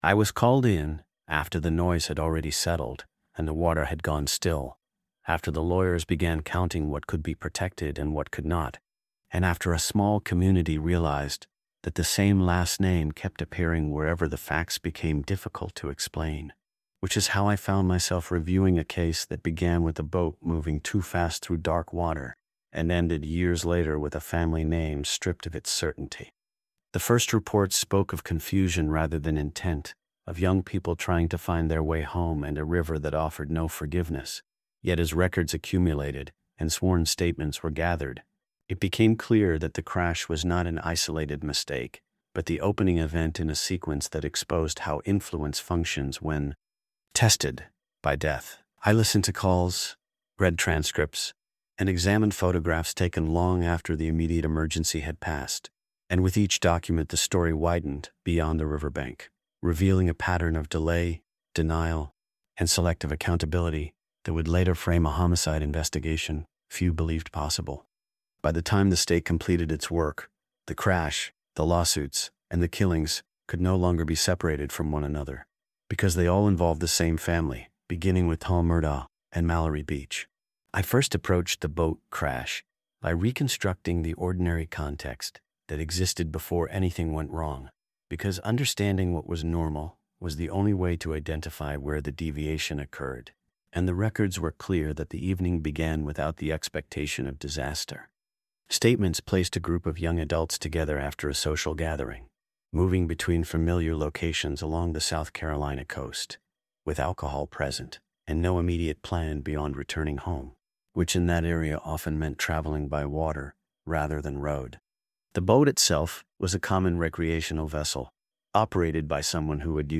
This episode of The Murder Tape Khronicles traces the verified chain of events that began with a fatal boat crash on a South Carolina river and ended years later with the collapse of a powerful legal family. Told from the perspective of a first-person detective narrator